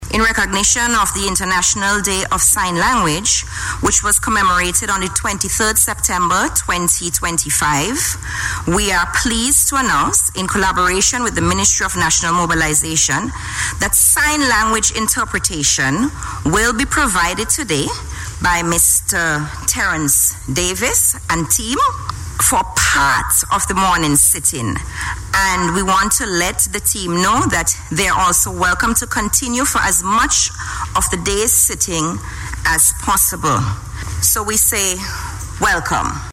Speaker of the House of Assembly, Rochelle Forde made the announcement at the start of today’s sitting.